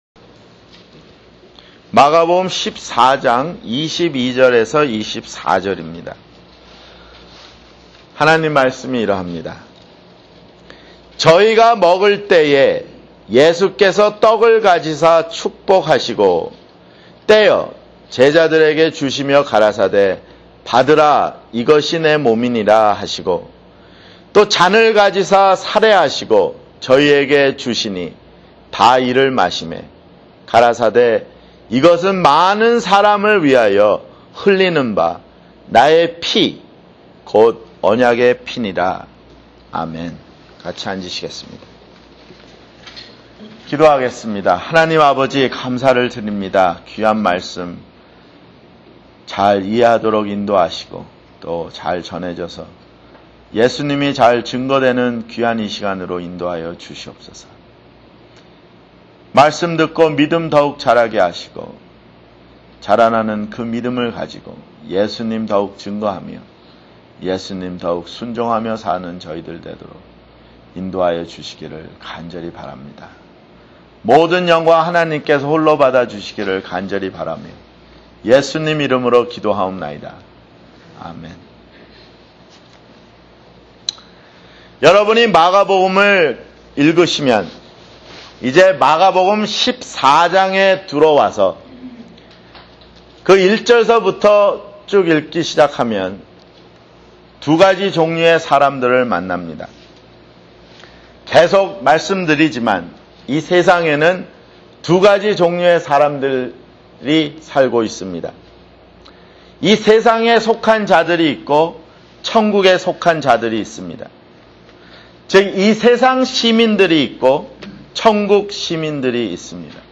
[주일설교] 마태복음 14:22-24